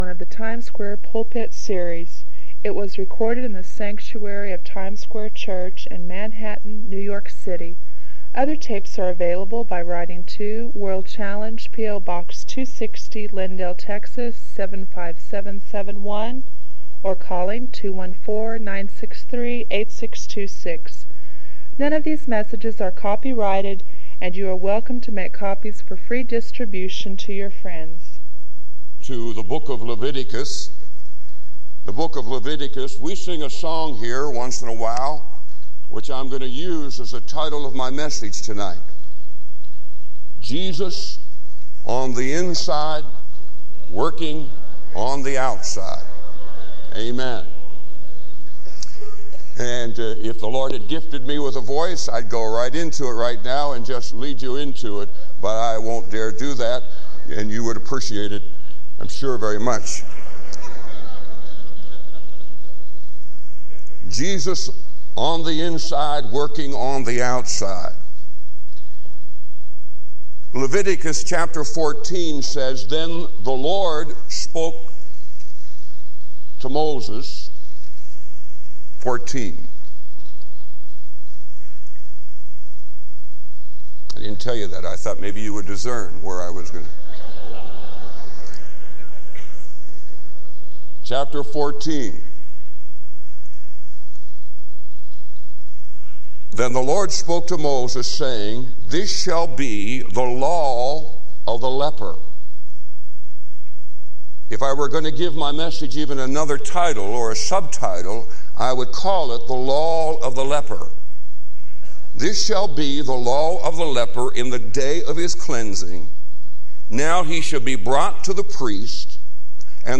It was recorded in the sanctuary of Times Square Church in Manhattan, New York City.